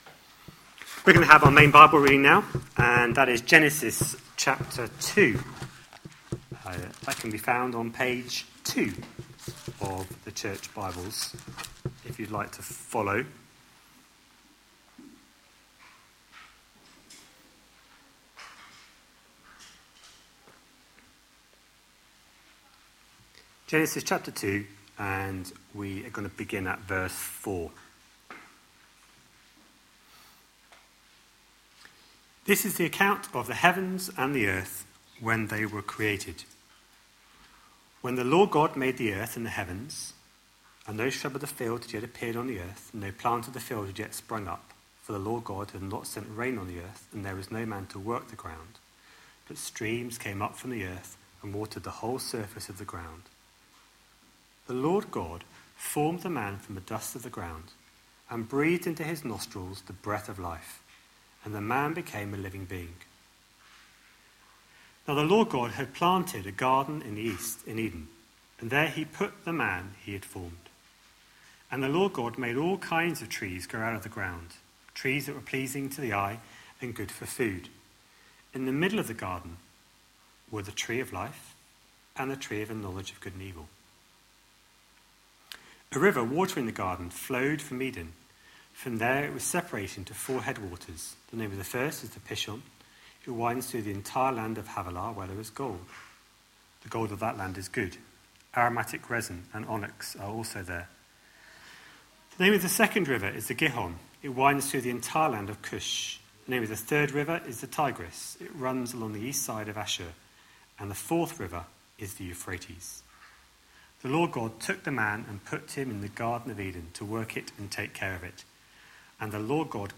A sermon preached on 9th October, 2016, as part of our Genesis: The origin of everything series.